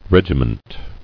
[reg·i·ment]